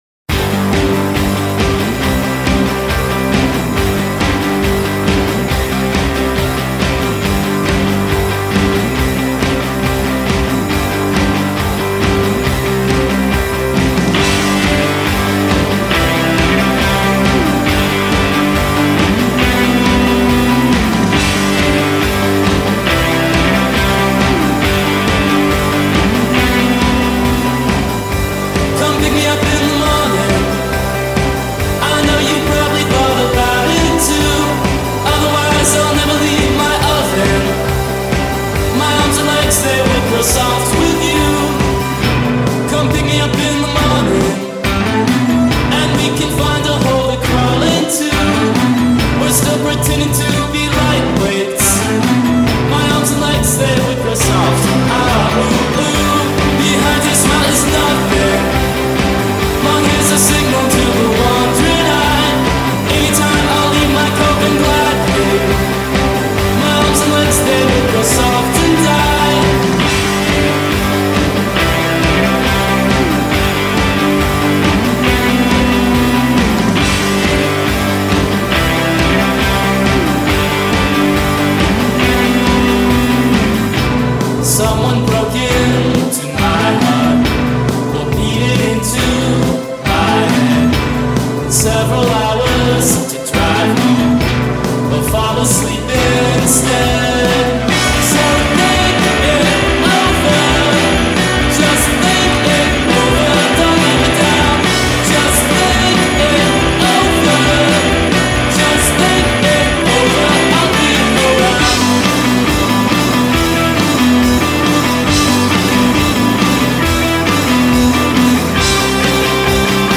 lo scintillio metallico di certi riverberi